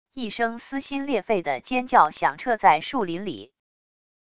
一声撕心裂肺的尖叫响彻在树林里wav音频